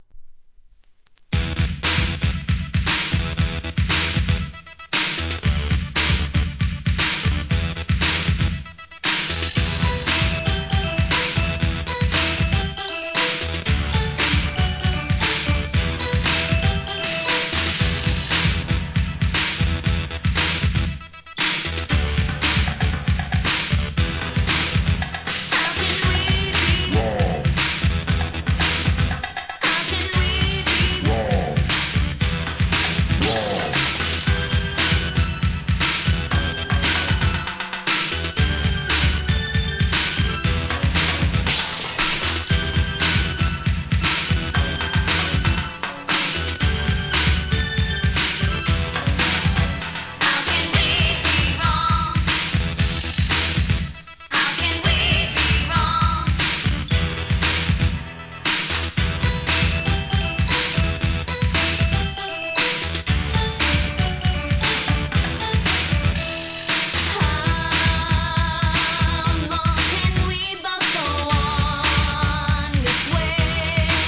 SOUL/FUNK/etc...
派手なDISCOナンバーが揃います。